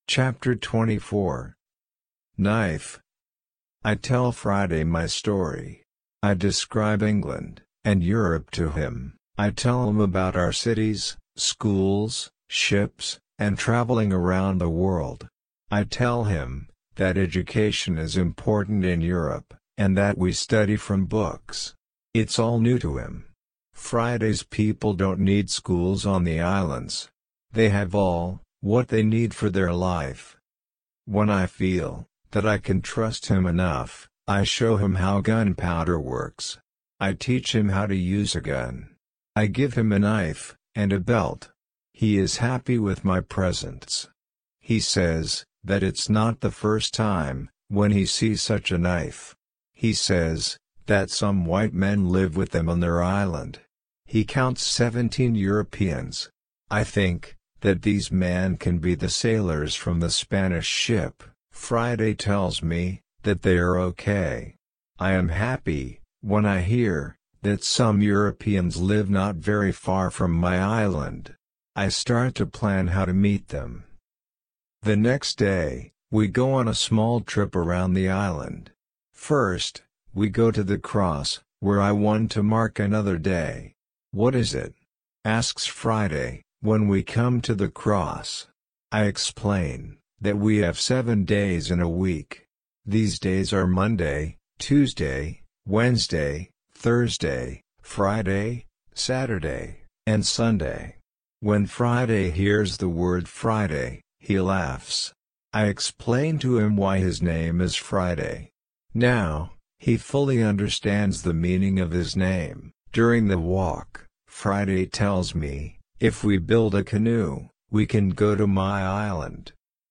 RC-L1-Ch24-slow.mp3